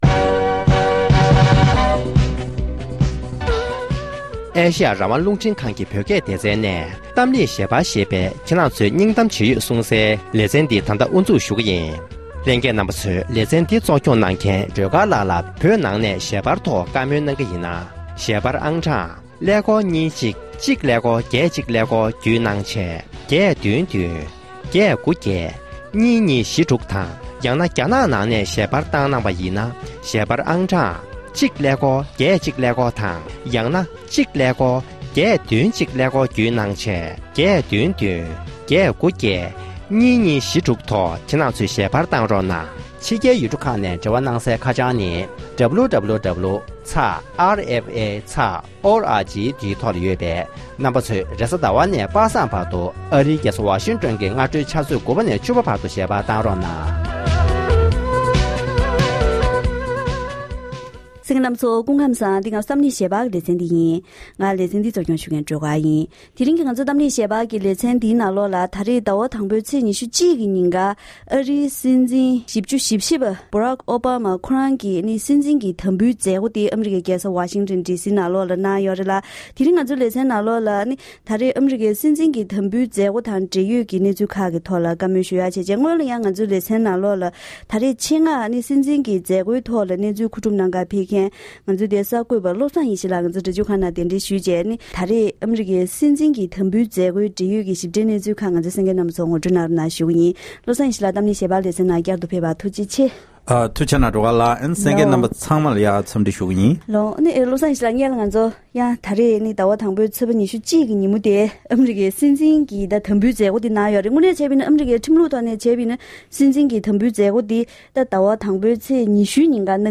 ༄༅༎དེ་རིང་གི་གཏམ་གླེང་ཞལ་པར་ལེ་ཚན་ནང་ཕྱི་ཟླ་༡ཚེས་༡༢རེས་གཟའ་ཟླ་བའི་ཉིན་ཨ་རིའི་སྲིད་འཛིན་བཞི་བཅུ་ཞེ་བཞི་པ་ཨོ་སྦ་མ་མཆོག་གི་སྲིད་འཛིན་གྱི་དམ་འབུལ་མཛད་སྒོ་གནང་བའི་གནས་ཚུལ་དང་། སྲིད་འཛིན་གྱི་ལས་ཡུན་གཉིས་པའི་ཐོག་བོད་དང་འབྲེལ་ཡོད་ཀྱི་གནད་དོན་ཁག་ལ་དབྱེ་ཞིབ་ཞུས་པ་ཞིག་གསན་རོགས་གནང་།།